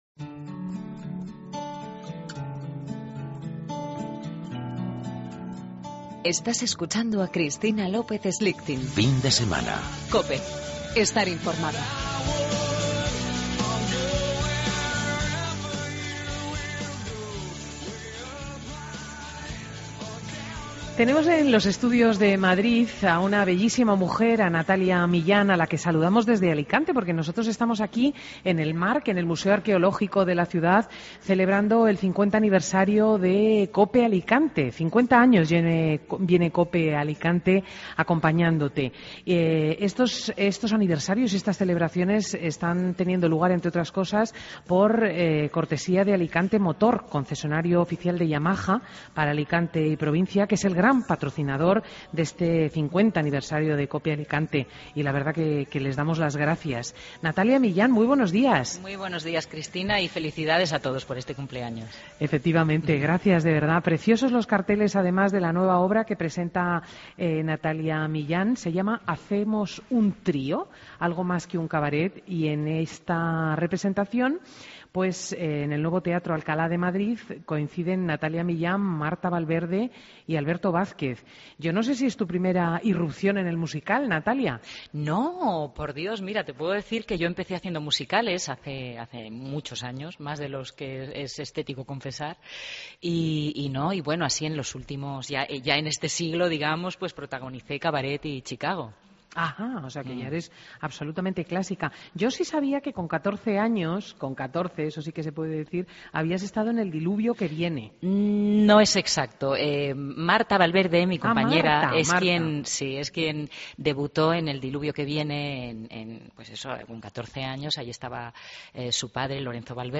Escucha la entrevista a la actriz Natalia Millán